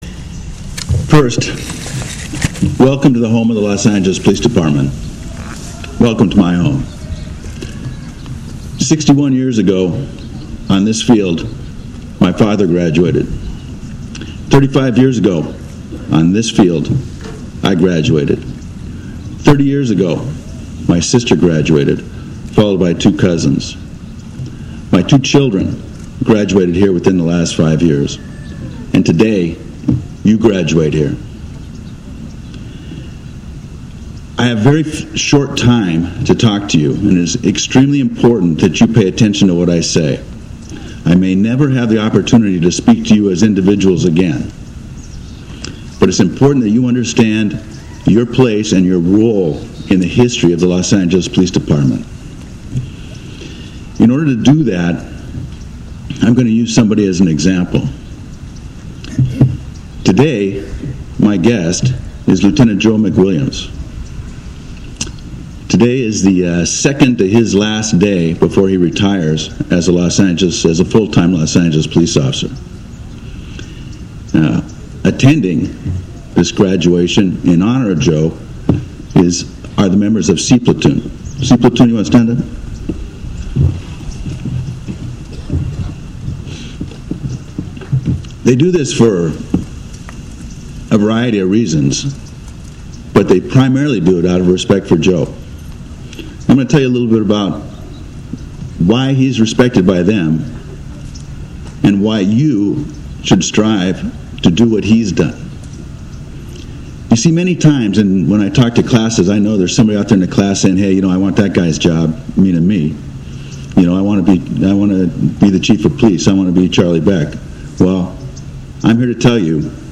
Today December 30, 2011, 39 new police officers marched across the Los Angeles Police Academy field.